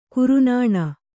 ♪ kurunāṇa